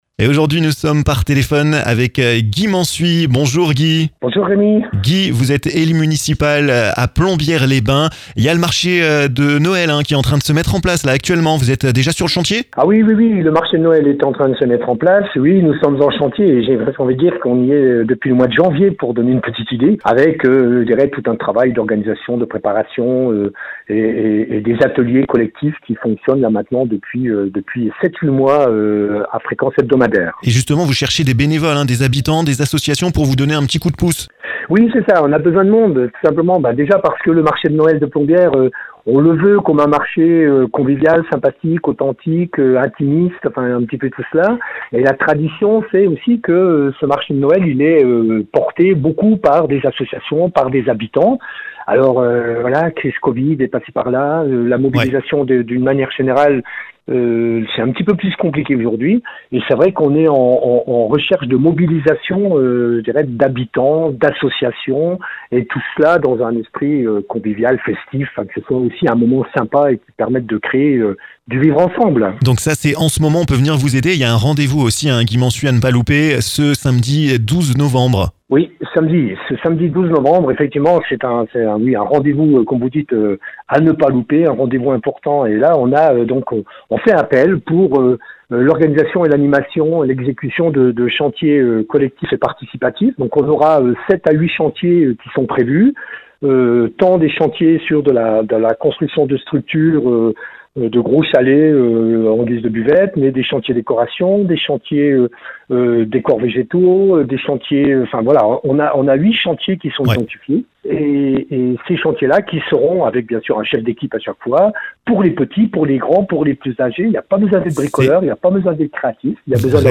%%La rédaction de Vosges FM vous propose l'ensemble de ces reportages dans les Vosges%%
Toutes les infos sont à retrouver dans ce podcast avec Guy Mansuy, élu municipal.